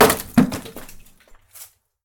wood_chop2.mp3